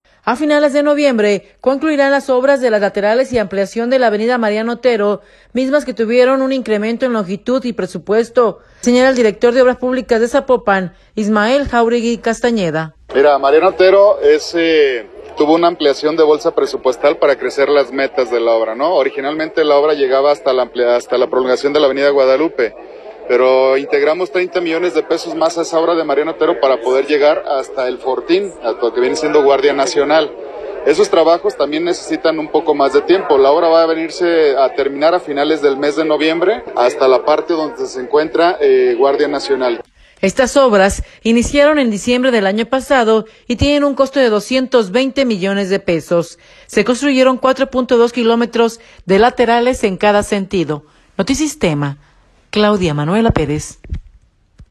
A finales de noviembre concluirán las obras de las laterales y ampliación de la avenida Mariano Otero, mismas que tuvieron un incremento en longitud y presupuesto, señala el director de Obras Publicas de Zapopan, Ismael Jauregui Castañeda.